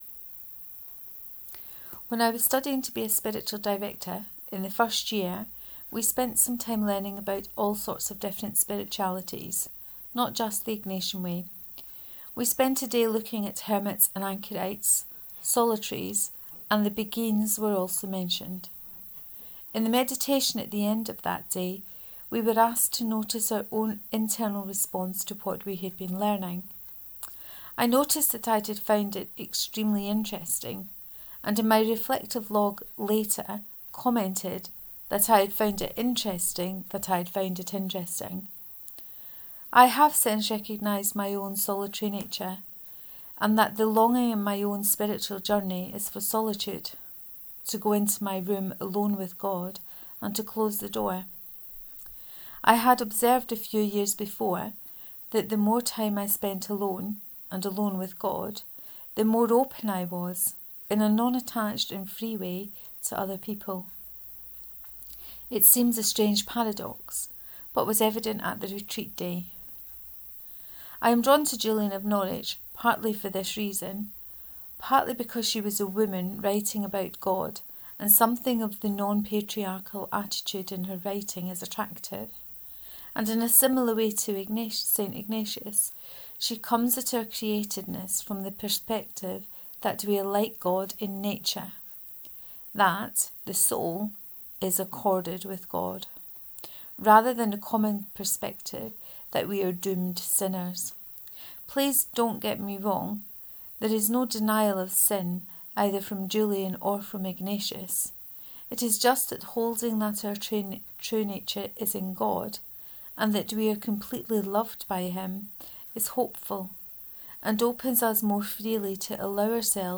Reading of All things in a hazelnut part 2